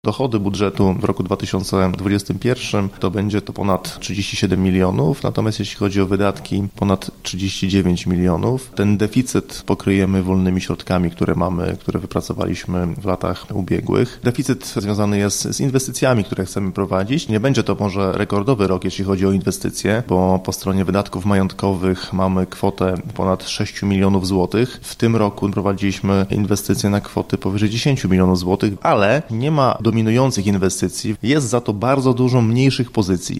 – Jesteśmy po spotkaniu z radnymi, gdzie omówiliśmy najważniejsze cele przyszłorocznego budżetu i planowane wydatki – mówi Paweł Lichtański, burmistrz Iłowej: